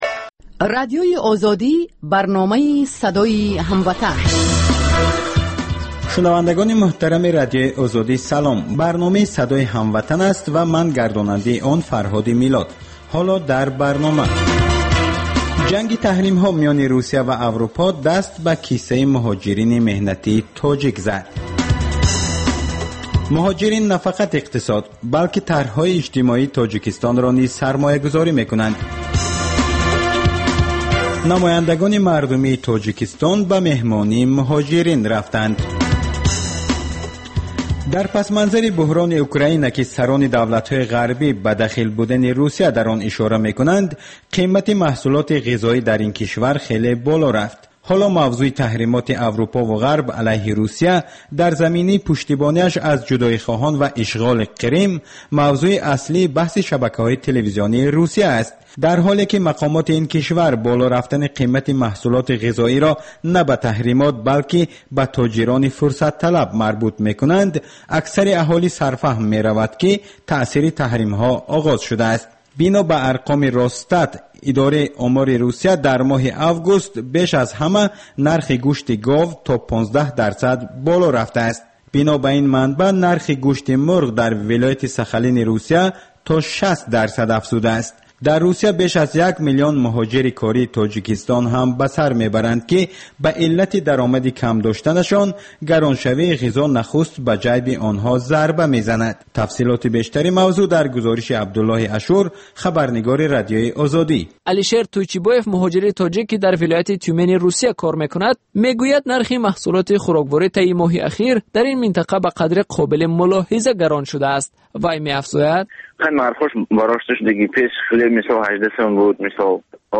Тоҷикон дар кишварҳои дигар чӣ гуна зиндагӣ мекунанд, намунаҳои комёб ва нобарори муҳоҷирон дар мамолики дигар, мусоҳиба бо одамони наҷиб.